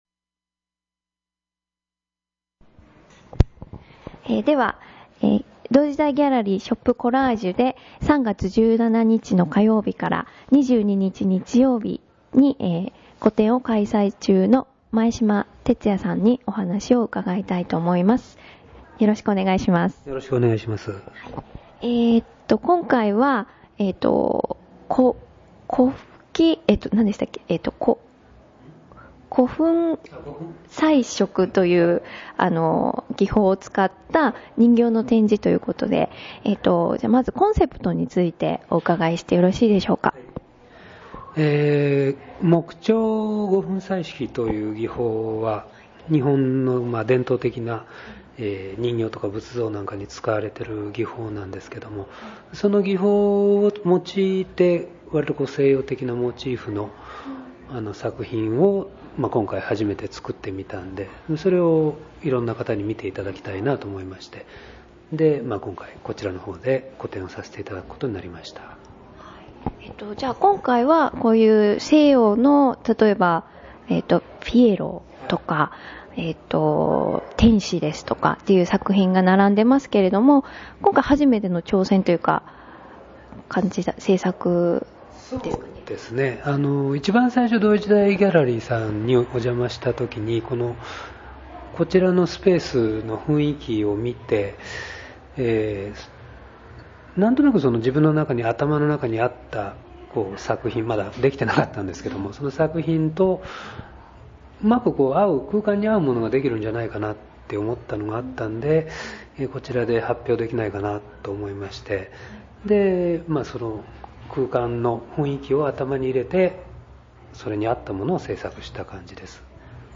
作品について詳しくインタビューしてきましたので お聴き下さい。